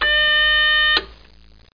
BEEPBUTT.mp3